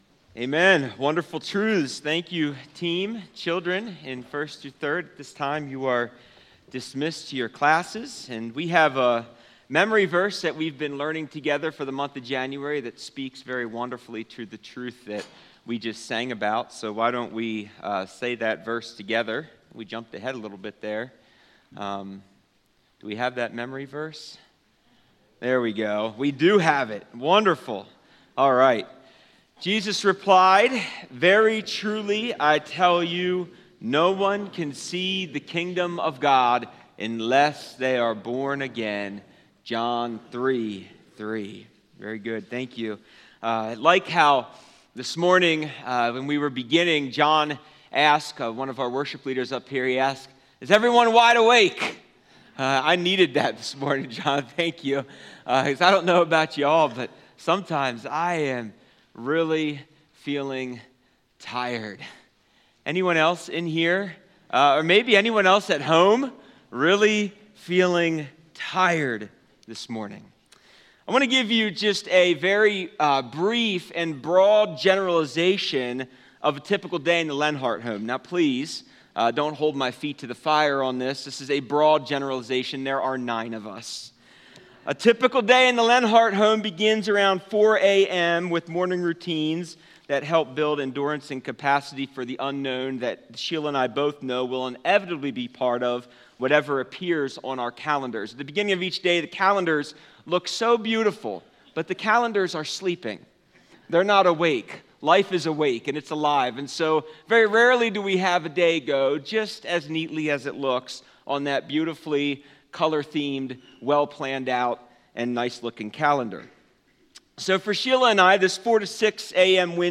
Sermons | Calvary Monument Bible Church